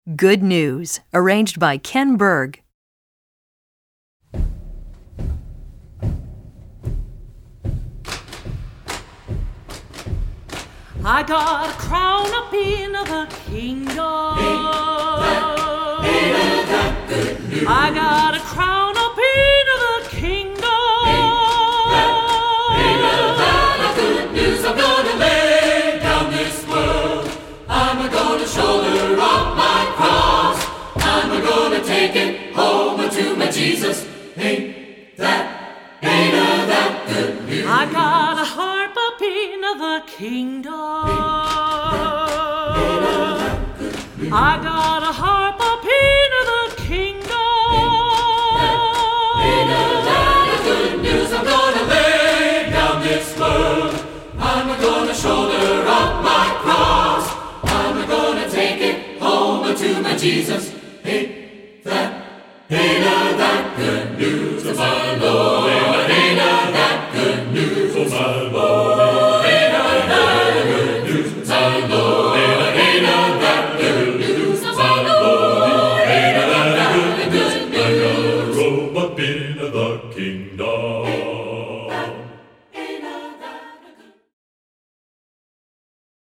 Composer: African-American Spiritual
Voicing: SATB a cappella